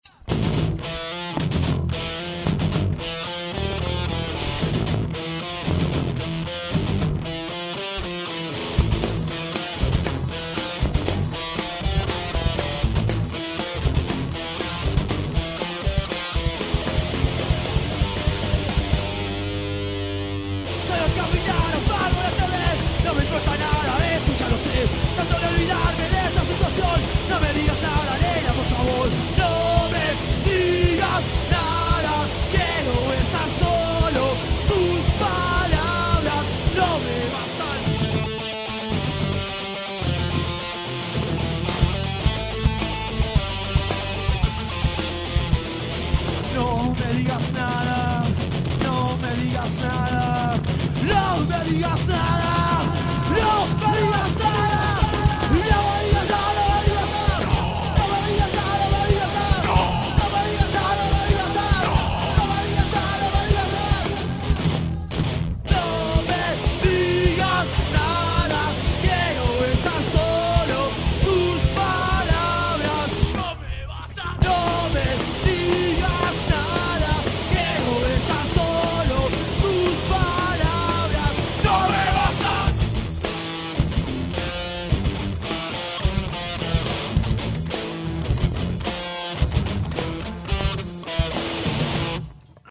Download punkhardcore Channels